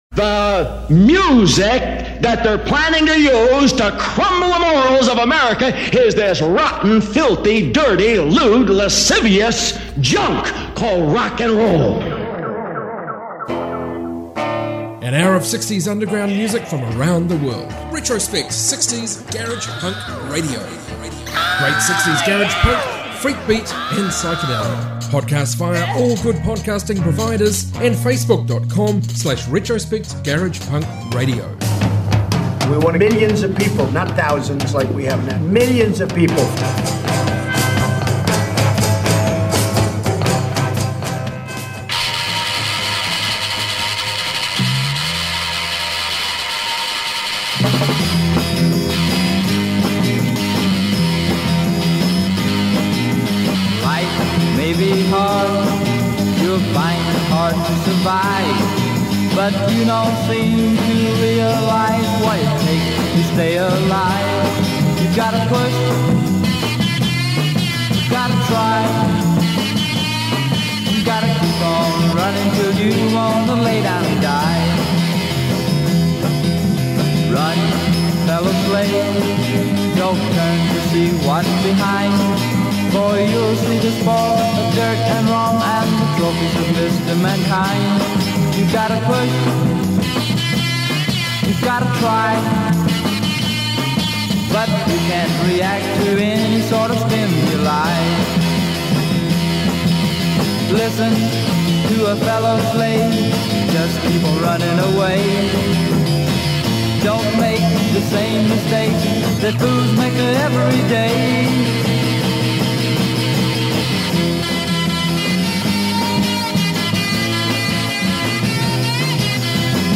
60s garage rock garage punk and beat music